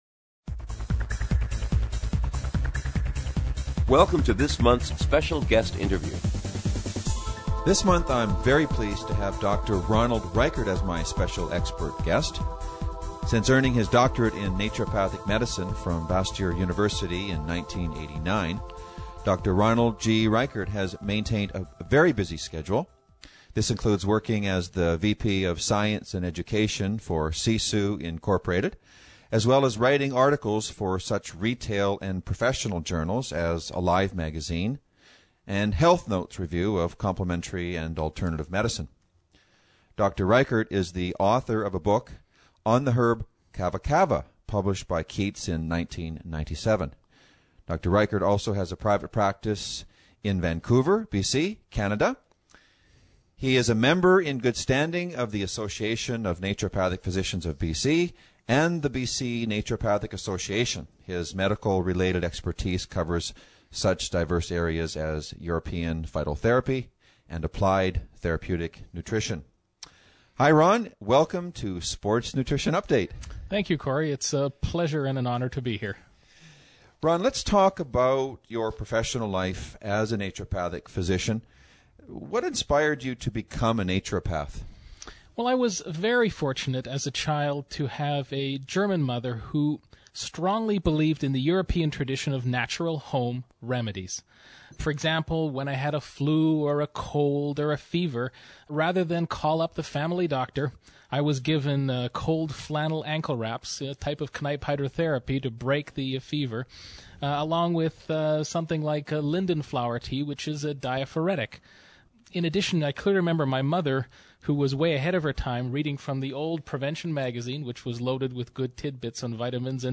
Special Guest Interview Volume 3 Number 5 V3N5c